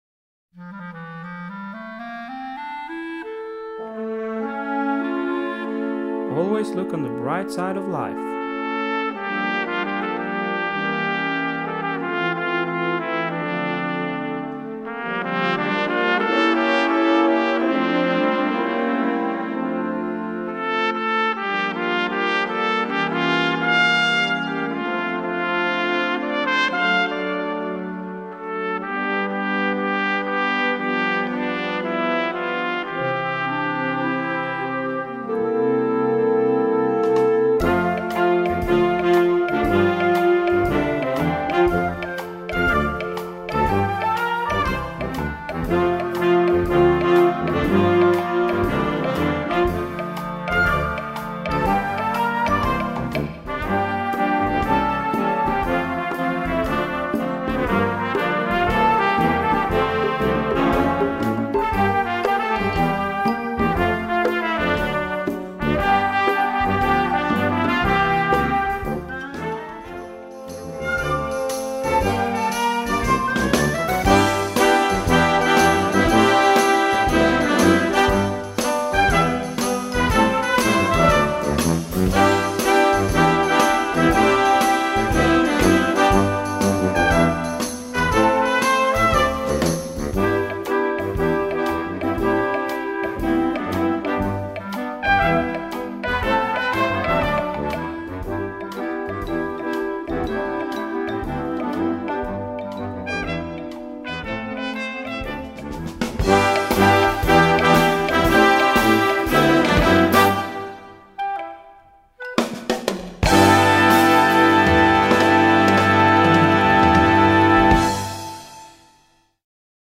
Gattung: Modernes Jugendwerk
Besetzung: Blasorchester